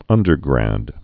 (ŭndər-grăd) Informal